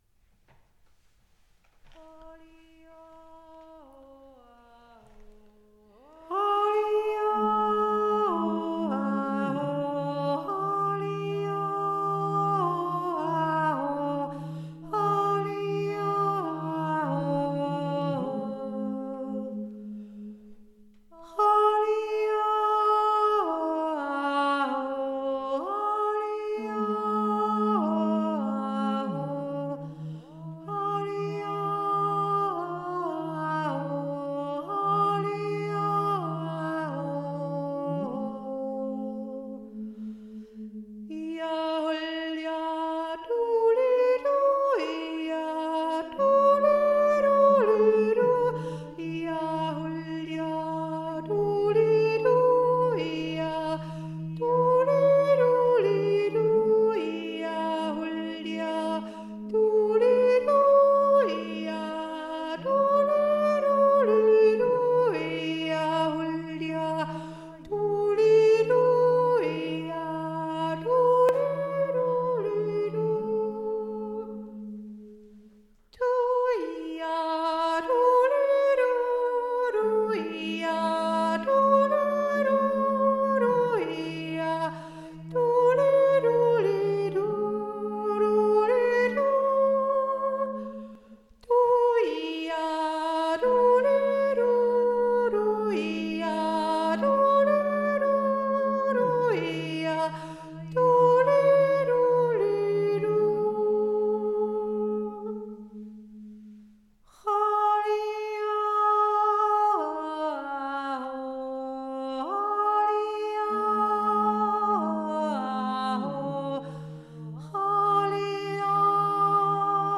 hohe Stimme
z-umn-sch-hohe-stimme.mp3